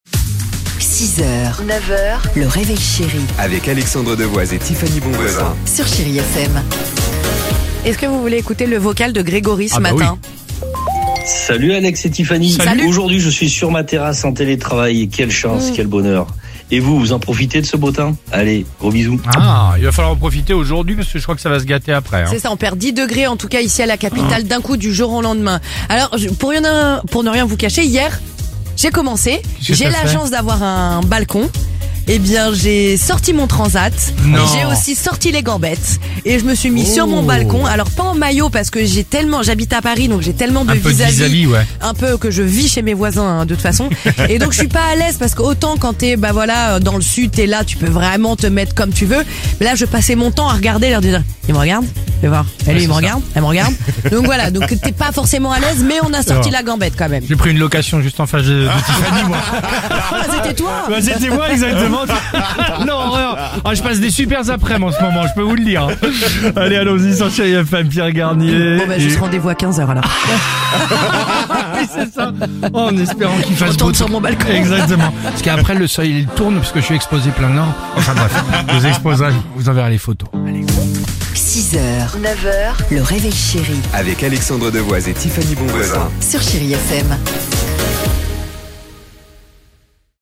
Tous les matins à 6h35 on écoute vos messages laissés sur l'insta et FB du réveil Chérie !